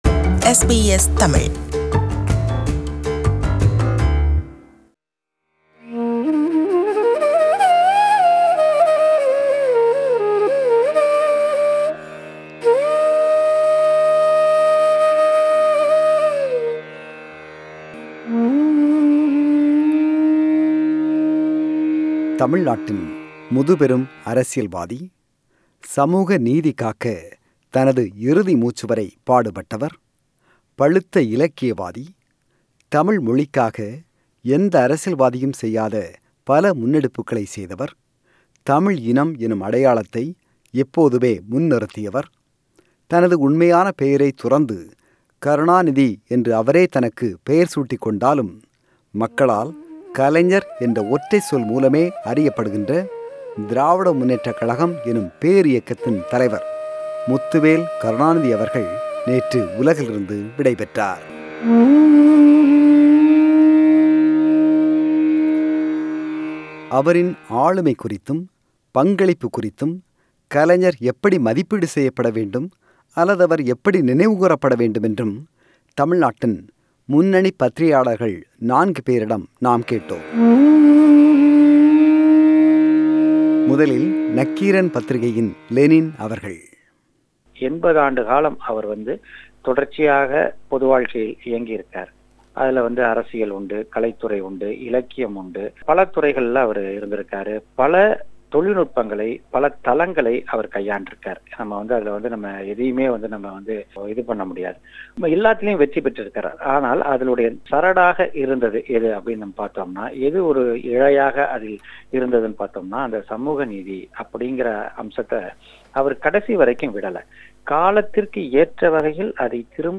Muthuvel Karunanidhi, a five-time chief minister of Tamil Nadu and the DMK president for 50 years, died in Chennai yesterday. Veteran and senior journalists